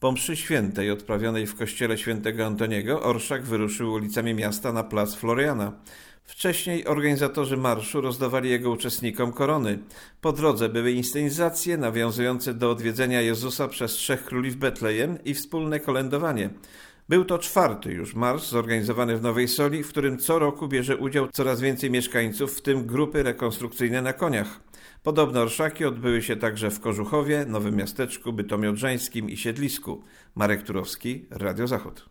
Na ulicach miasta było wspólne kolędowanie i jasełka, a na Placu Floriana występy artystyczne.